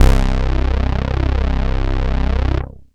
ANALOG 1.wav